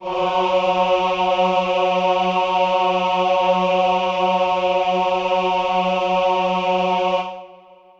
Choir Piano
F#3.wav